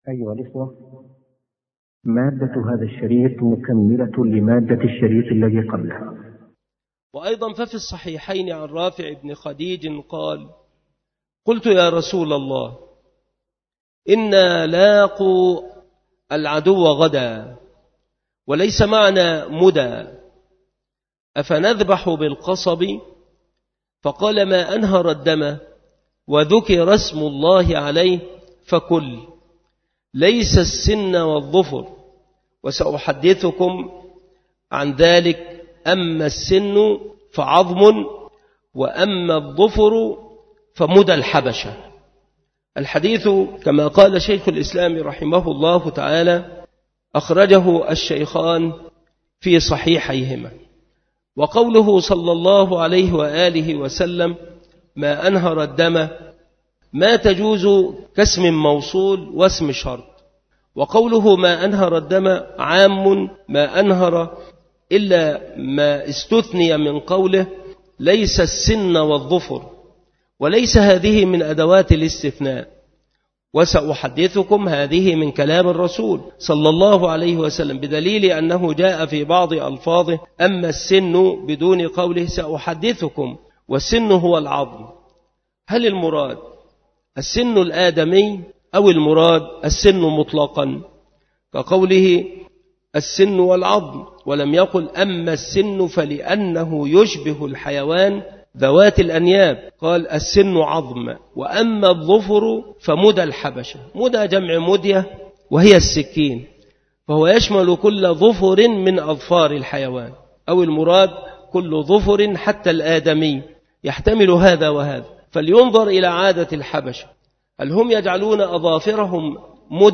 المحاضرة
مكان إلقاء هذه المحاضرة بالمسجد الشرقي بسبك الأحد - أشمون - محافظة المنوفية - مصر